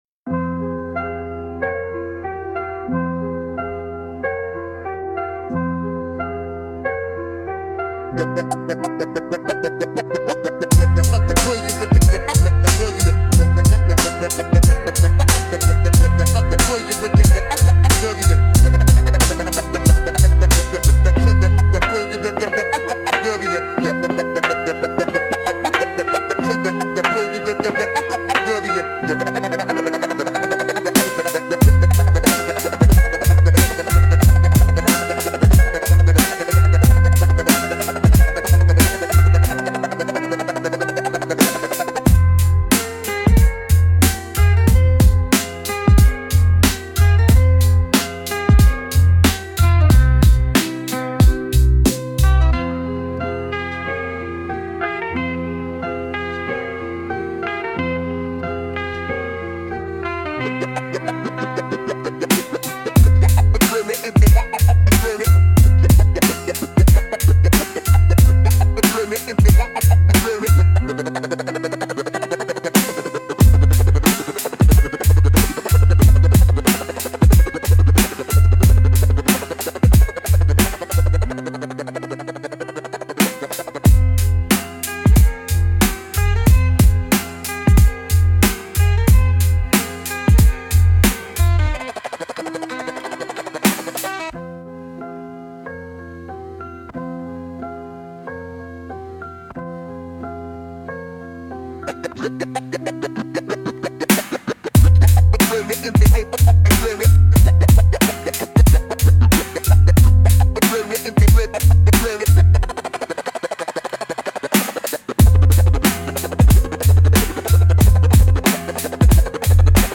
Instrumental - RUN from Those who Censor - Real Liberty Media dot XYZ - 4 .00 mins